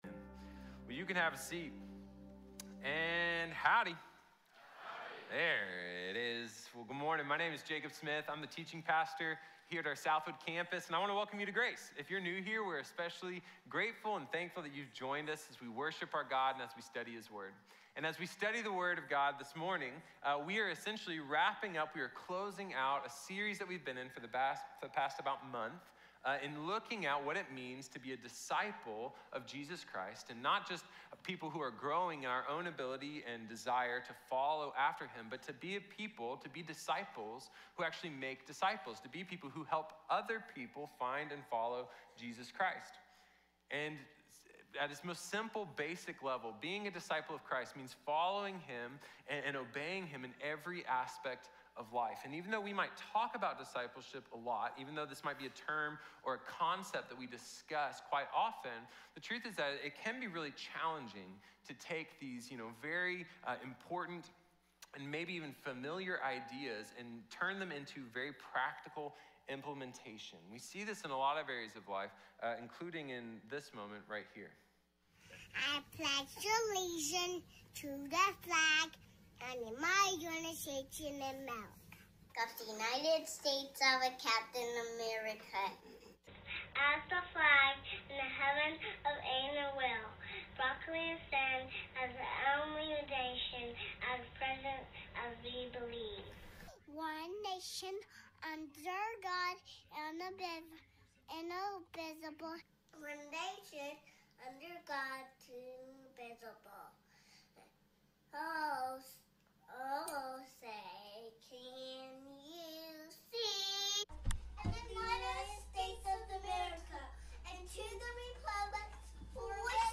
Multiplicación | Sermón | Iglesia Bíblica de la Gracia